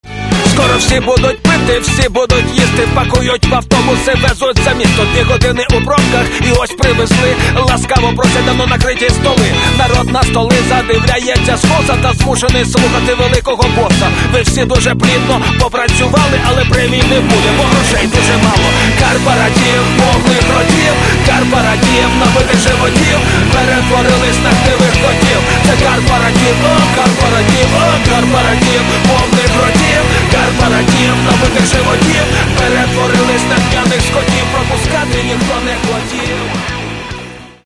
Каталог -> Хип-хоп